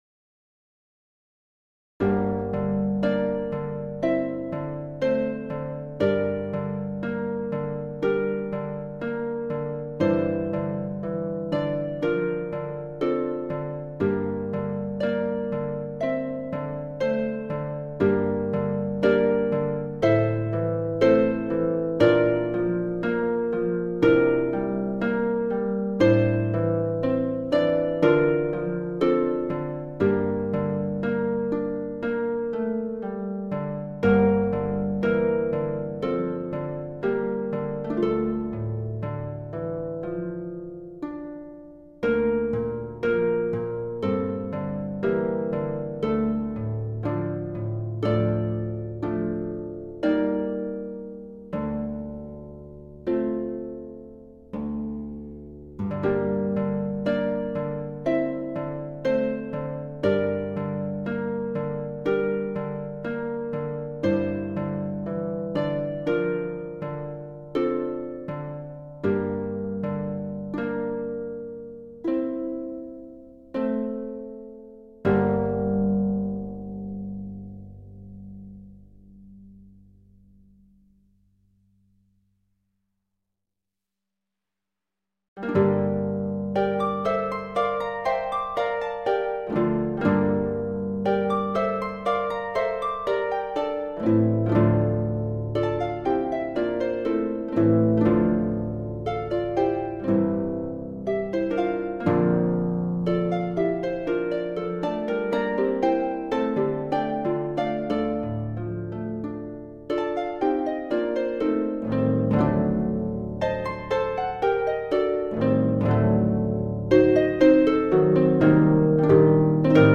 Variations sur un vieux Noël, pour harpe